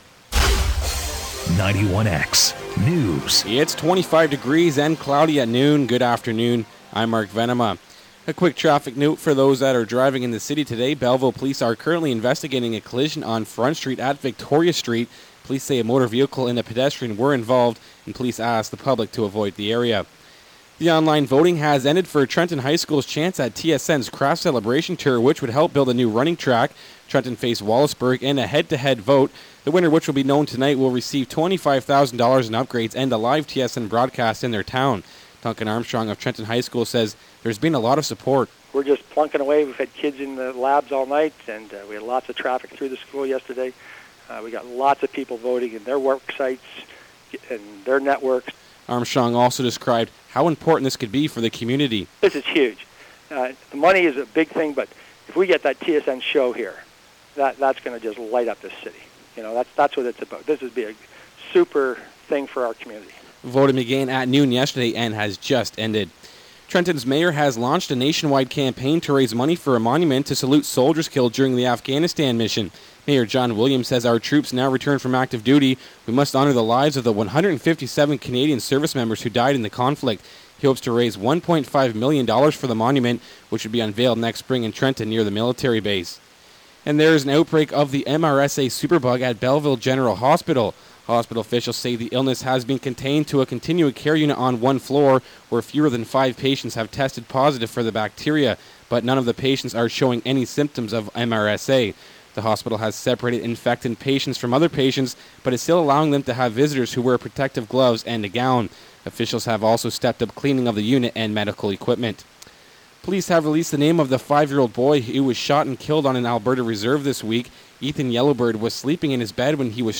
91x News, July 13, 2011, 12pm